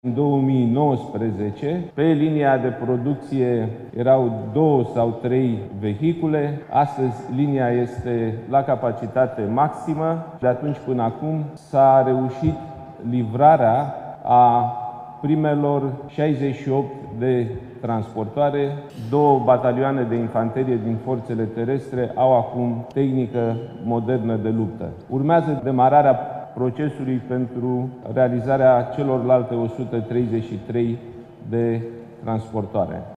Șeful Guvernului a participat marți, la ceremonia de semnare a unui acord între Uzina Mecanică București, filială a companiei de stat ROMARM și o companie privată, General Dynamics European Land Systems Romania: